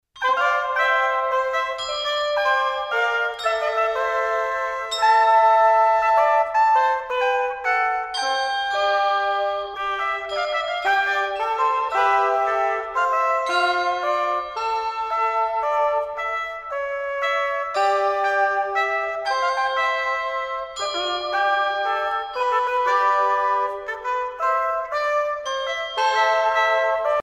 Musique médiévale